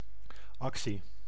Ääntäminen
IPA: [ak.siː]